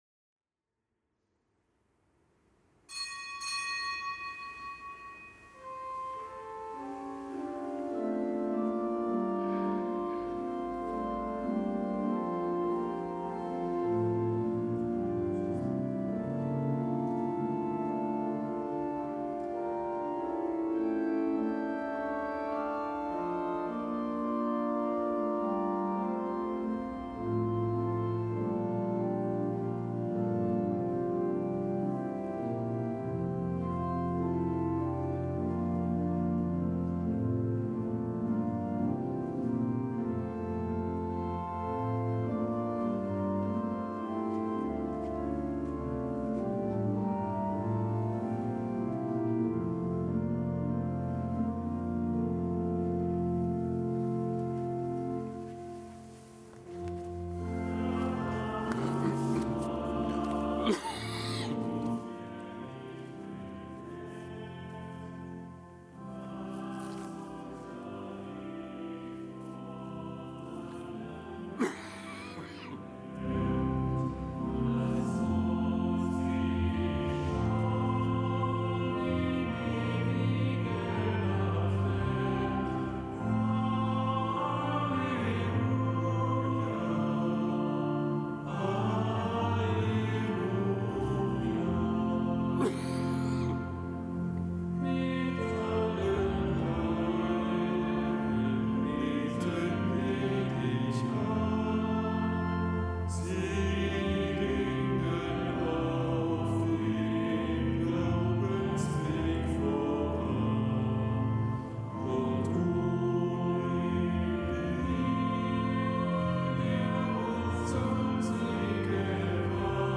Kapitelsmesse aus dem Kölner Dom am Gedenktag Hl. Johannes vom Kreuz, Ordenspriester, Kirchenlehrer. Zelebrant: Weihbischof Ansgar Puff.